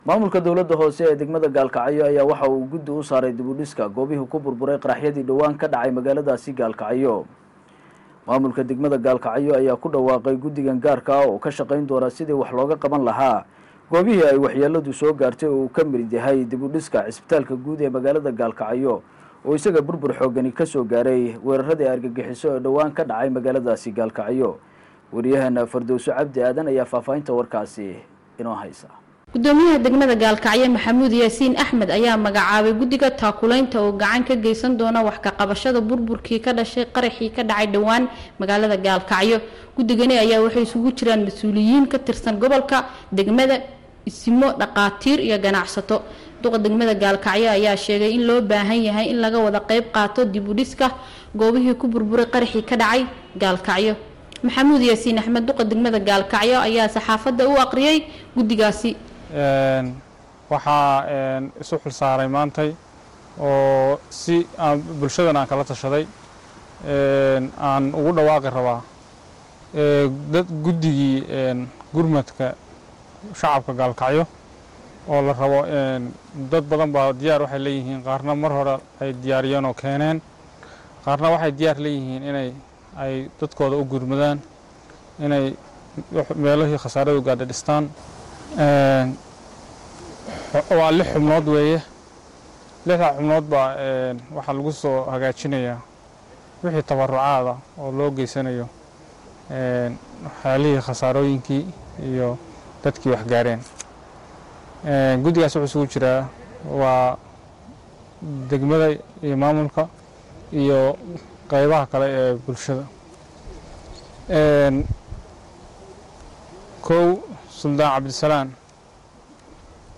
Dhagayso Gudoomiyaha Degmada Gaalkacyo Maxamuud Yaasiin Tumey oo sheegaya inuu Magacaabay Guddiga!!